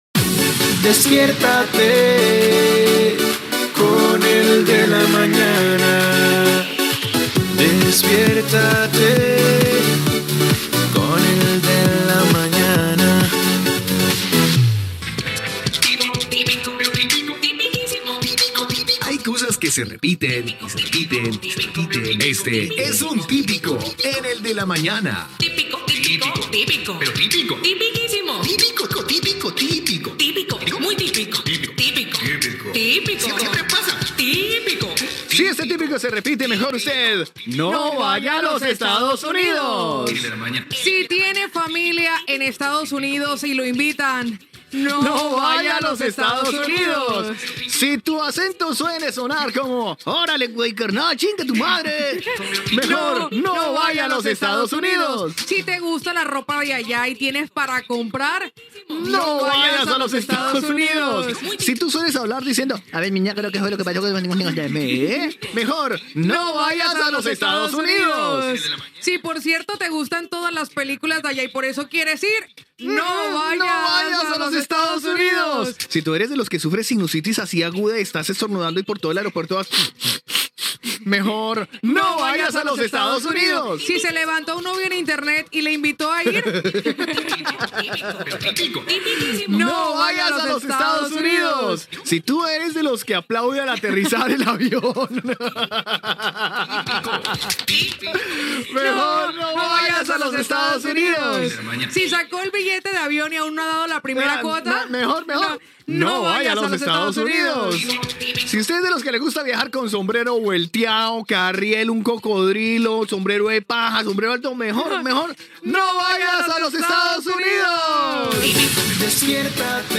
Identificació del programa, secció humorística "Típico", dedicada a motius per no anar als EE.UU., indicatiu del programa
Entreteniment
FM